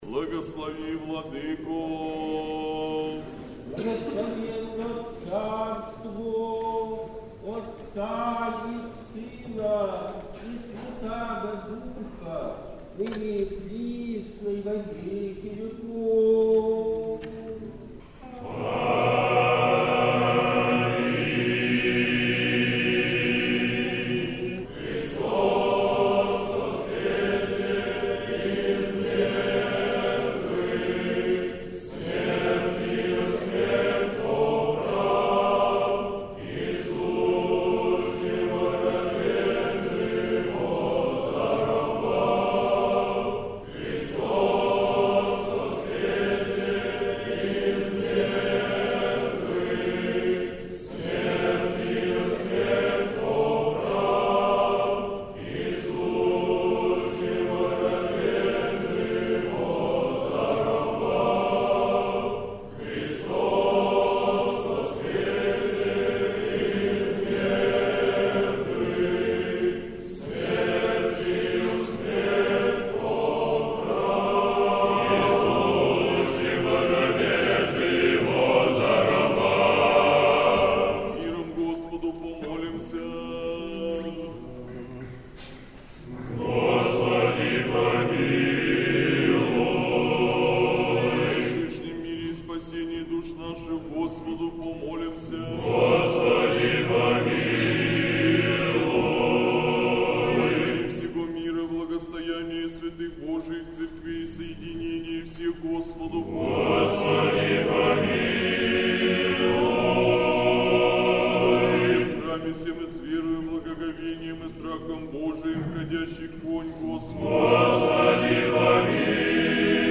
Сретенский монастырь. Божественная литургия. Хор Сретенского монастыря.